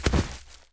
minf_drop1.wav